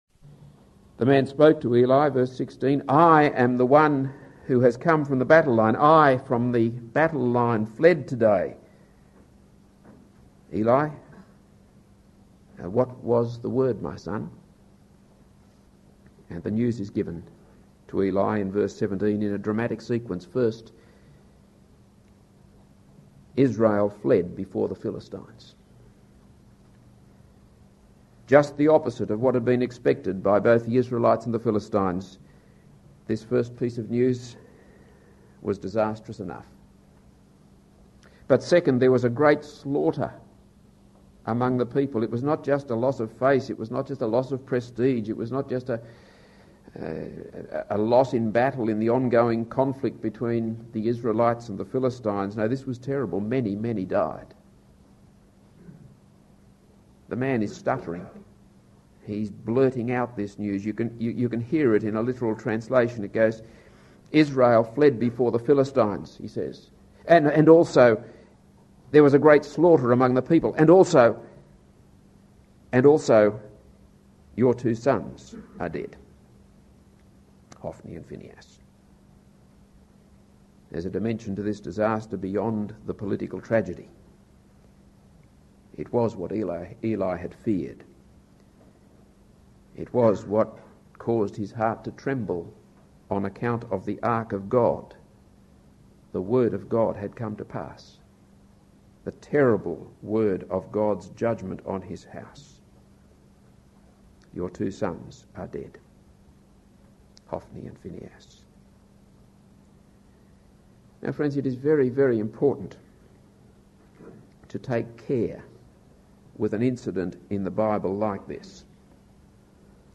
This is a sermon on 1 Samuel 4:12-22.